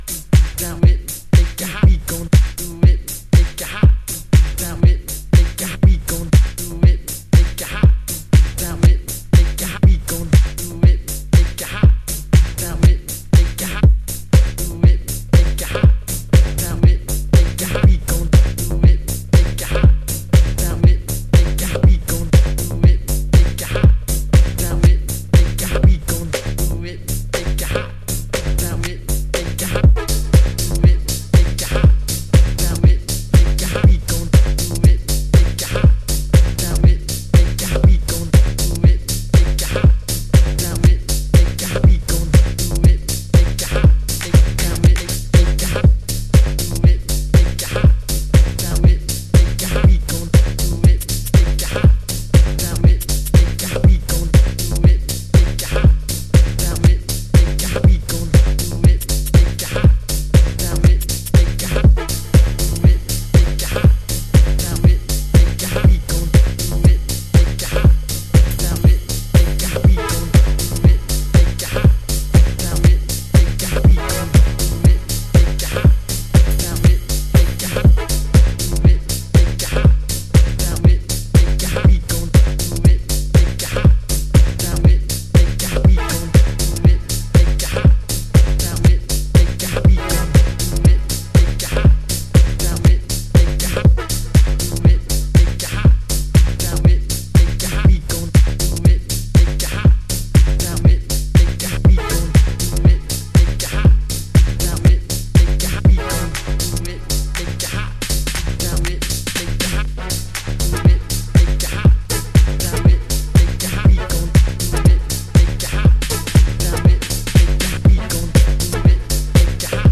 頭から尻尾まで執拗なヴォイスサンプルと剥き出しのリズム、シンプルなシンセワークの抜き差しでRAW & LOWにグルーヴ。
House / Techno